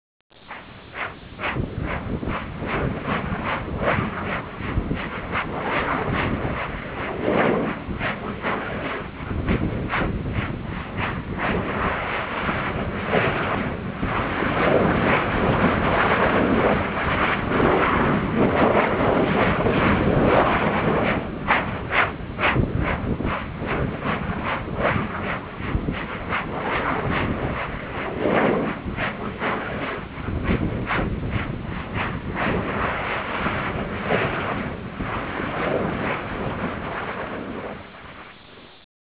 respiro vulcano.wav